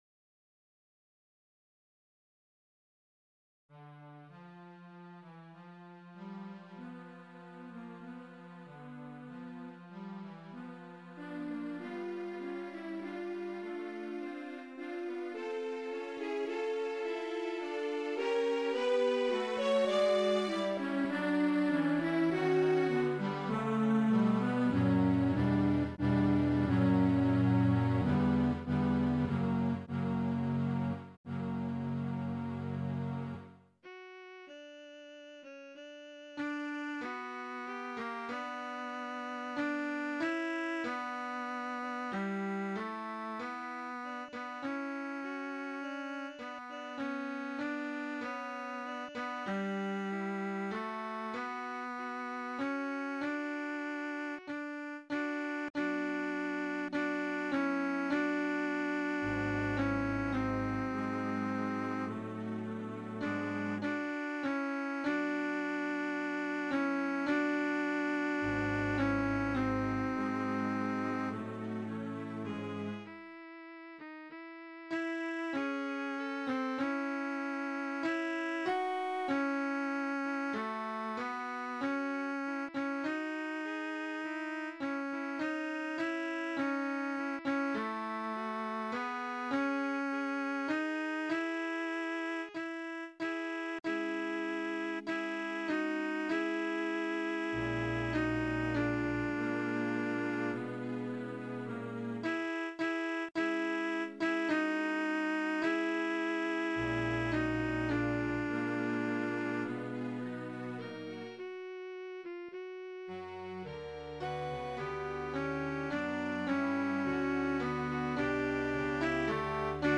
Faure tracks – tenor